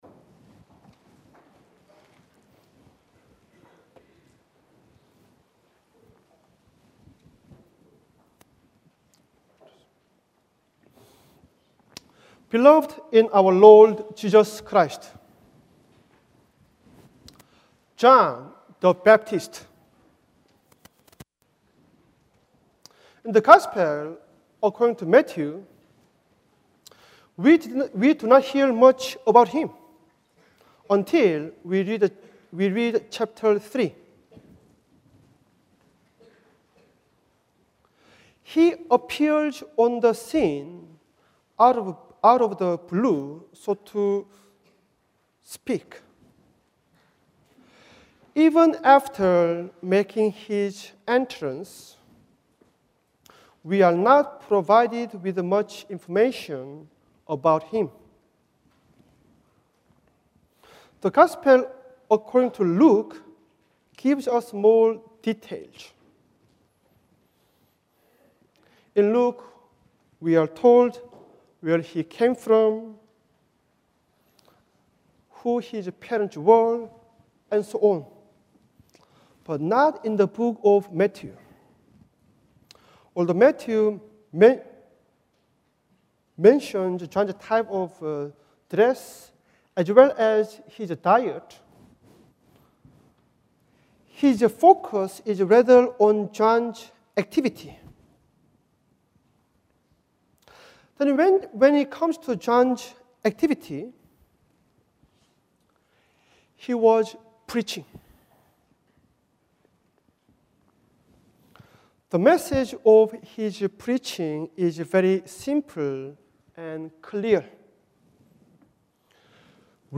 Isaiah 61:1-3 Service Type: Sunday Morning Bible Text